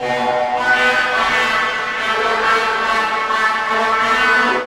18 GUIT 2 -R.wav